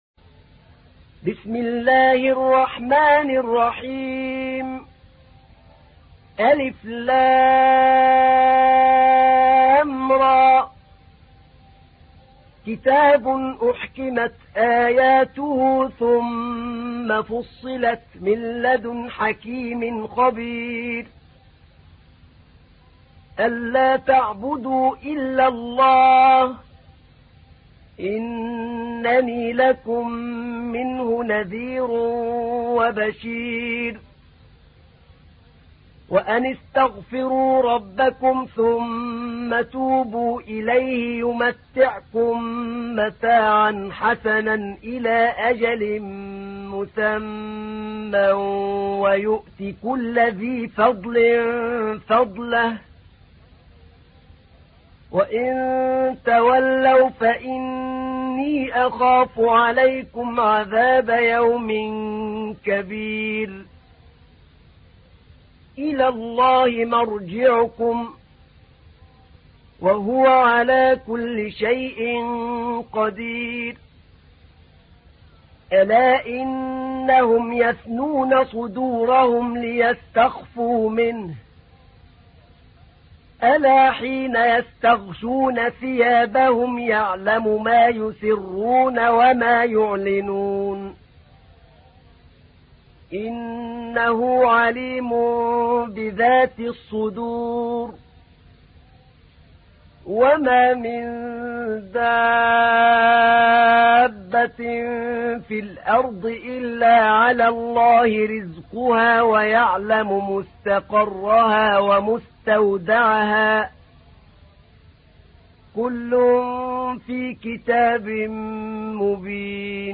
تحميل : 11. سورة هود / القارئ أحمد نعينع / القرآن الكريم / موقع يا حسين